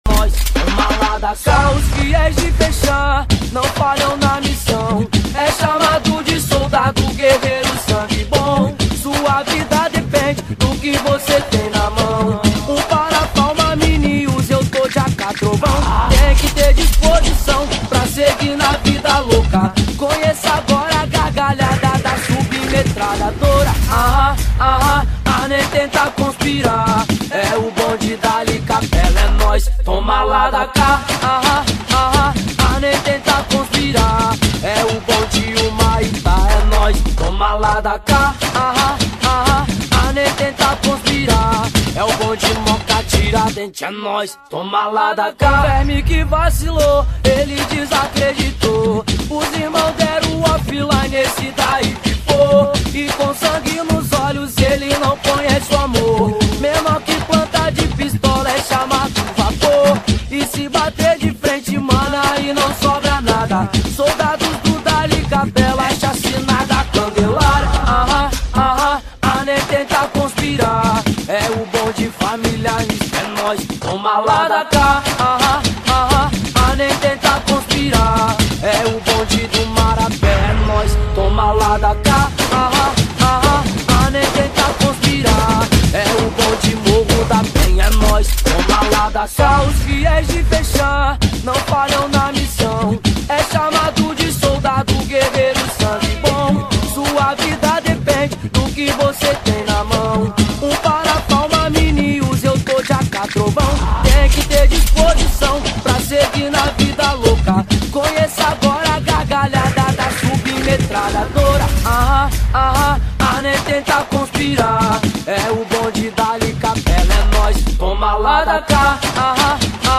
2025-04-07 08:19:34 Gênero: Funk Views